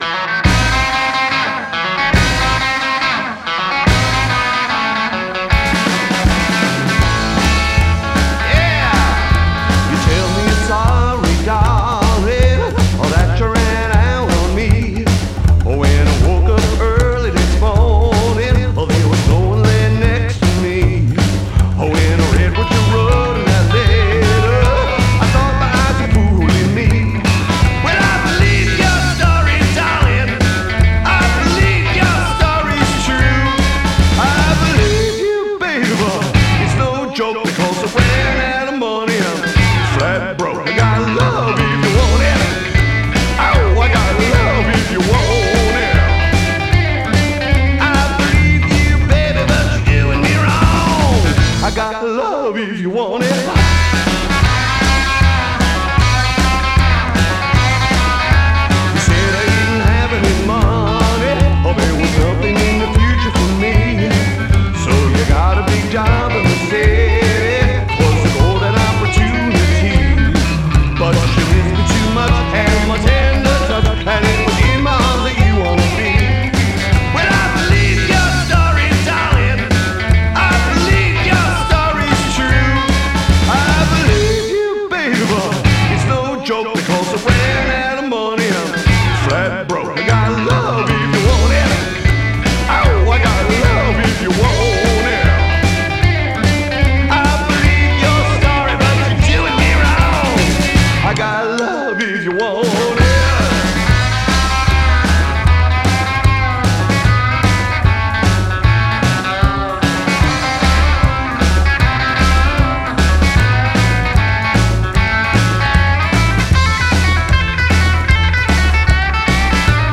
Bass, Vocals
Drums, Vocals
Guitar, Vocals